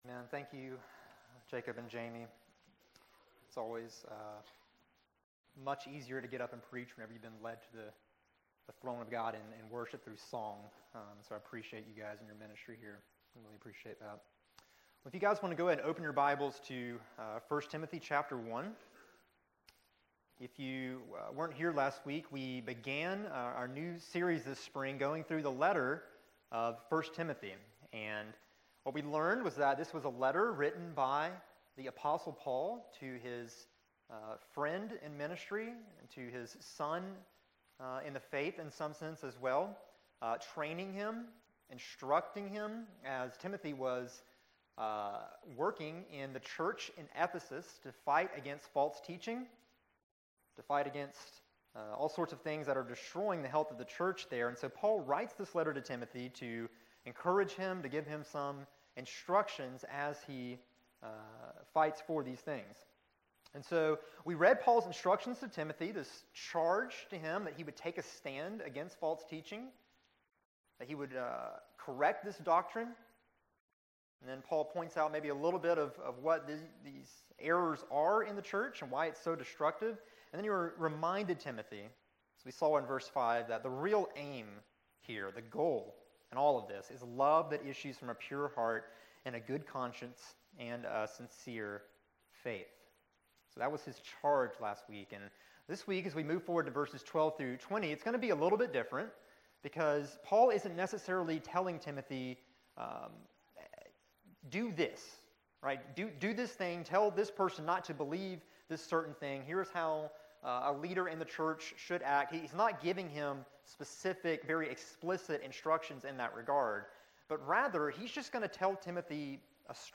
January 17, 2016 Morning Worship | Vine Street Baptist Church